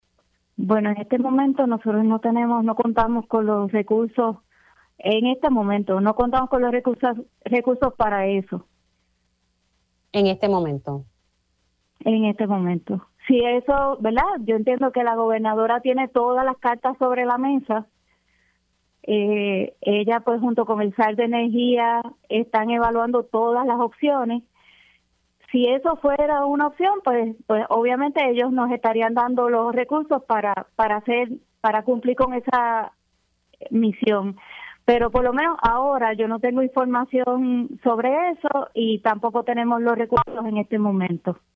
La directora ejecutiva de la Autoridad de Energía Eléctrica (AEE), Mary Carmen Zapata reconoció en Pega’os en la Mañana que si se cancelara el contrato de LUMA Energy hoy, no cuentan con los recursos para administrar la distribución y transmisión energética.